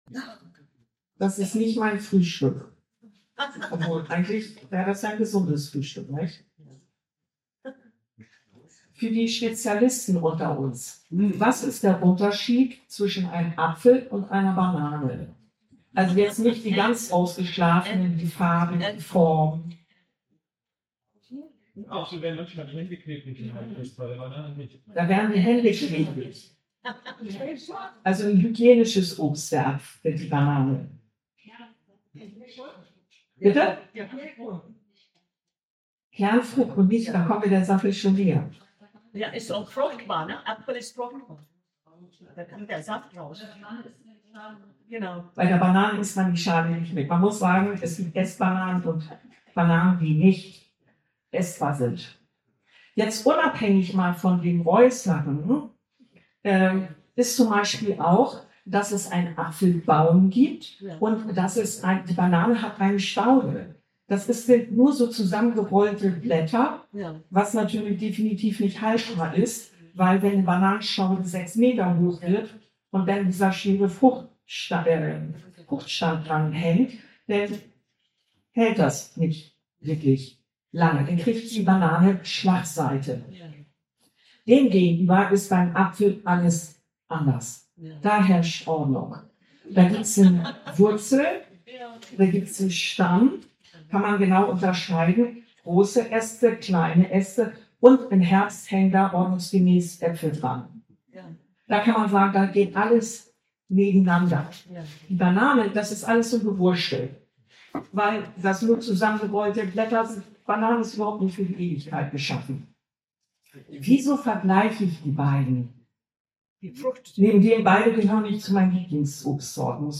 Womit ist unser geistliches Leben vergleichbar? Die Audio-Aufzeichnung der Predigt wird später evtl. ersetzt durch eine qualitativ bessere Version.